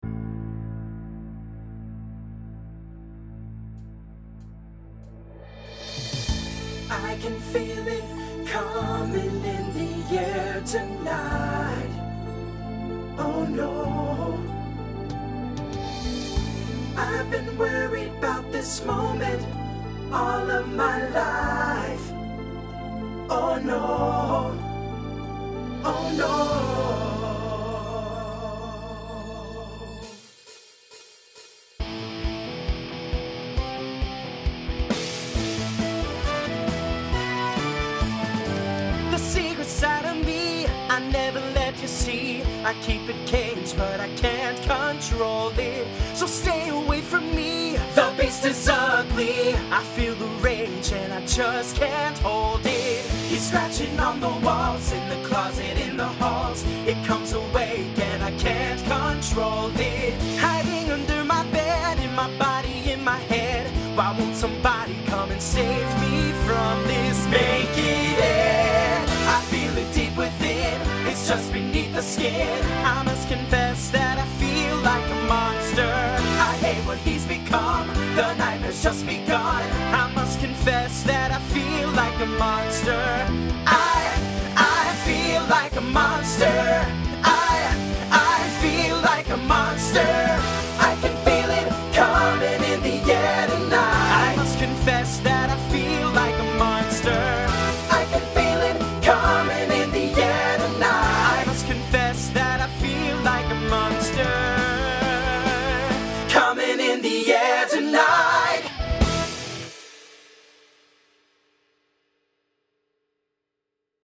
Voicing SATB Instrumental combo Genre Rock
2000s Show Function Mid-tempo